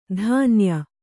♪ dhānya